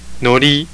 有聲發音